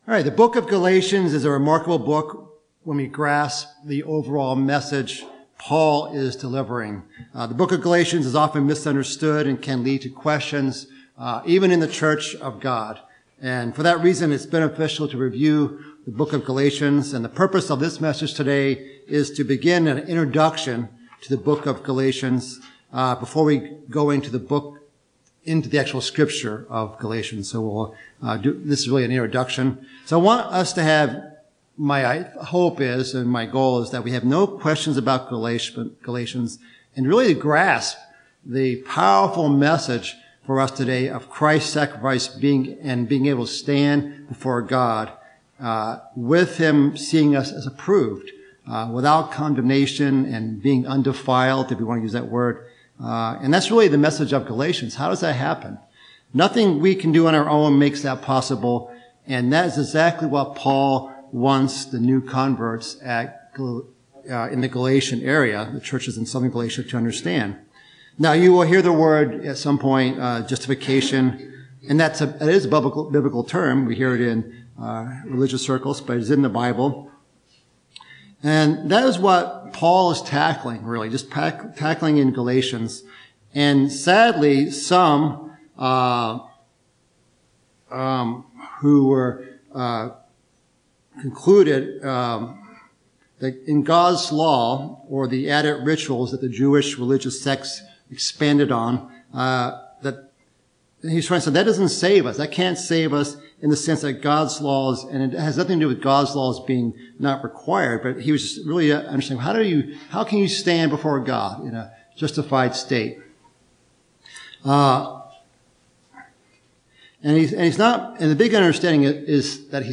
The first in a series of sermons on Galatians. This introduction covers 3 key principles in understanding the book of Galatians. It is a very often misunderstood book of the Bible, and one that can even be hard for God's converted people to understand.